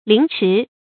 陵迟 ling chi